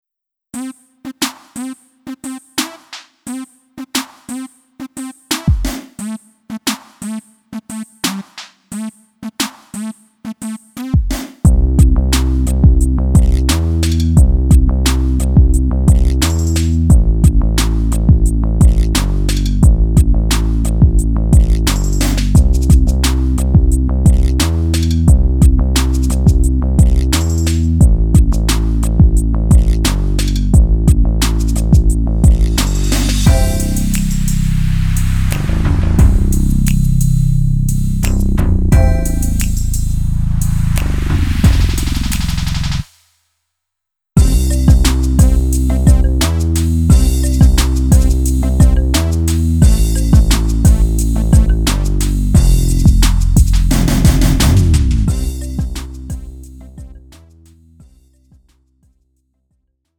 음정 원키 3:11
장르 가요 구분 Lite MR